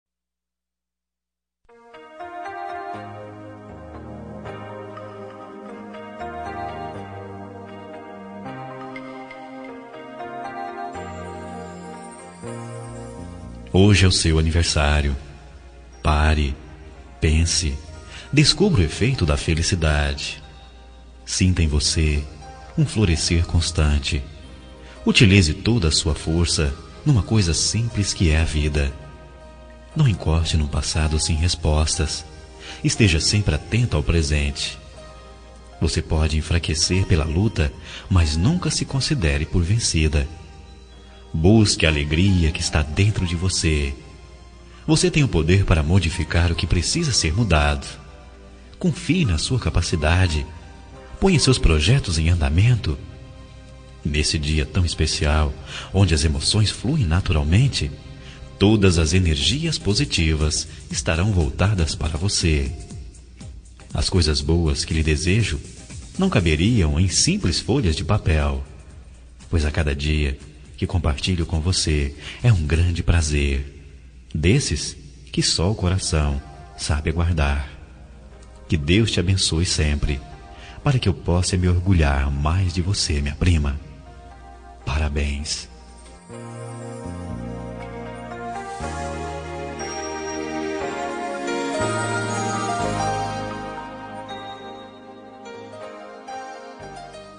Aniversário de Prima – Voz Masculina – Cód: 042816